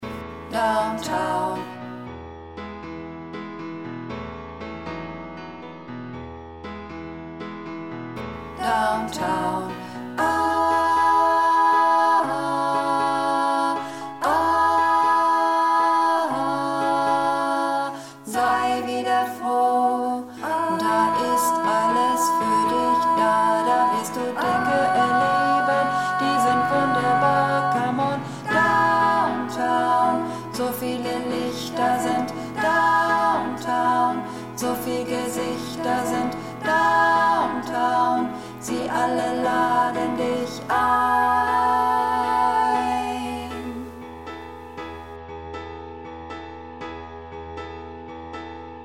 �bungsaufnahmen - Downtown